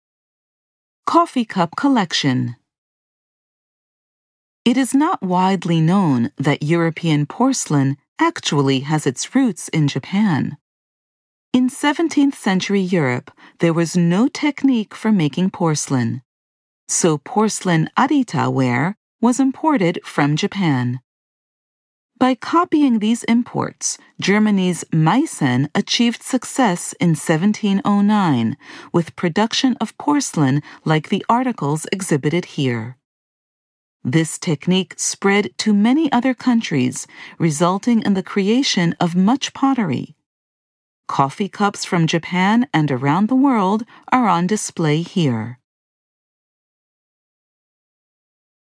Audio exhibition guide